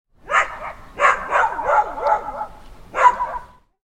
Dog Barking With Echo Sound Effect
A dog barks from the neighboring yard, creating a realistic outdoor atmosphere. The echo of the barking carries from nearby houses, adding depth to the sound.
Dog-barking-with-echo-sound-effect.mp3